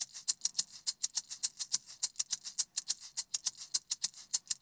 CWS SHAKER.wav